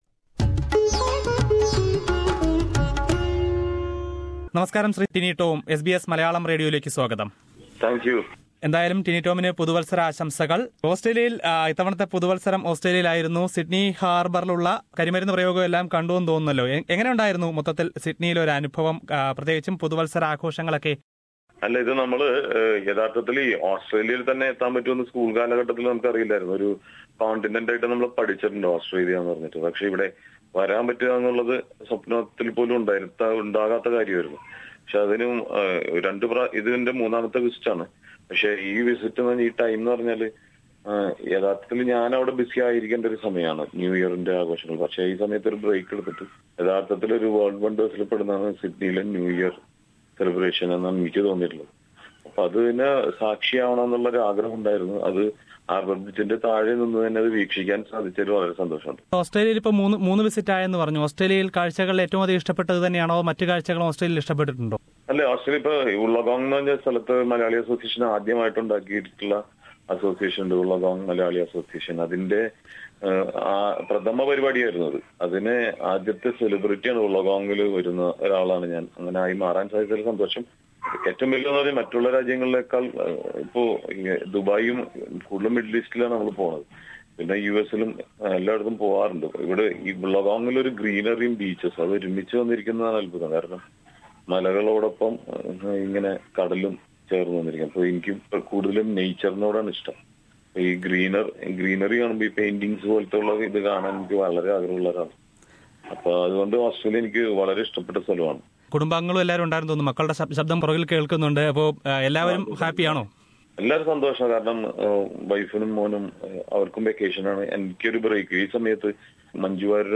Here is an interview with popular mimicry and cine artist Tini Tom who was in Sydney last week. Listen to Tini Tom who speaks about his experience in both the industries...